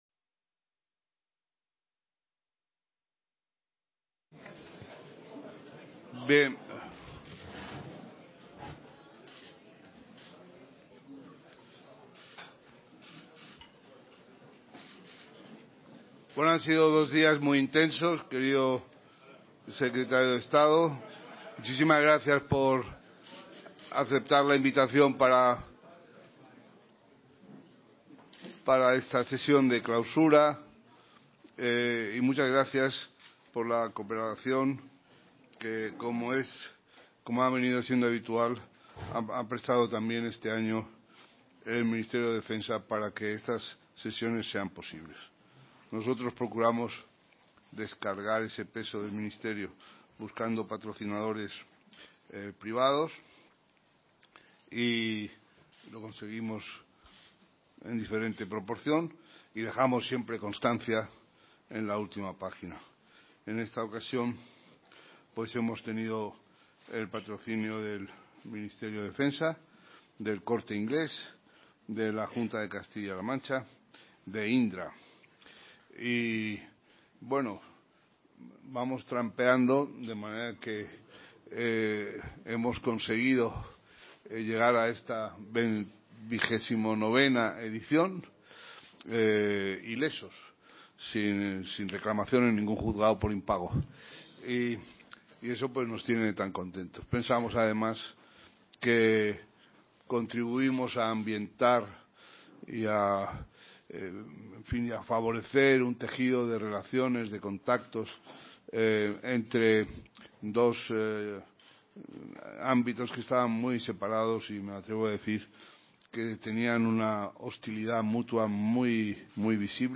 Parador de Toledo. 6 y 7 de Junio de 2017
07-sesiondeclausura.mp3